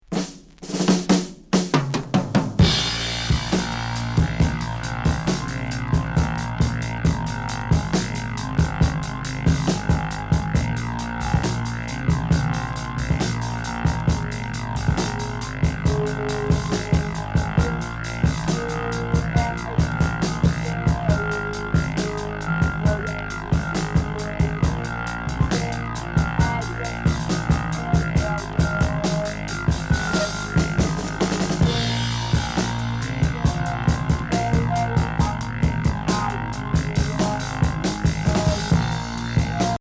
Progressif Unique 45t